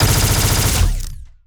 Plasmid Machinegun
GUNAuto_Plasmid Machinegun Burst_01_SFRMS_SCIWPNS.wav